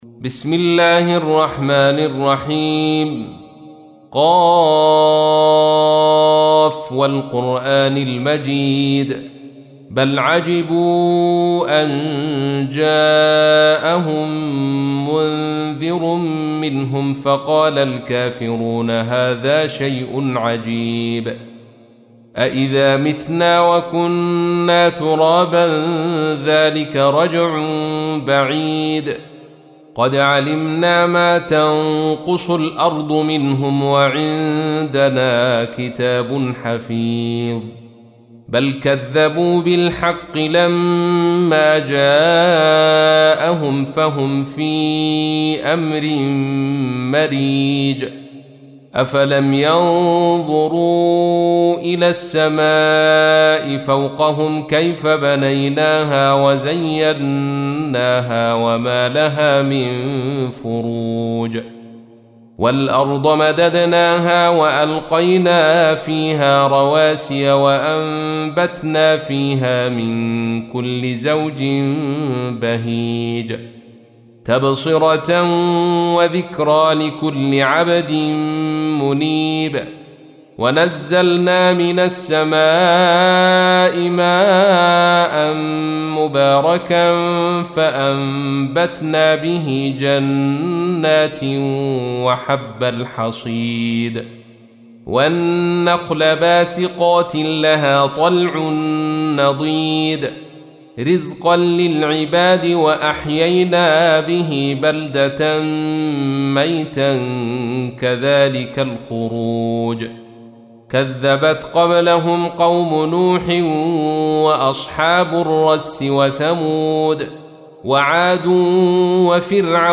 تحميل : 50. سورة ق / القارئ عبد الرشيد صوفي / القرآن الكريم / موقع يا حسين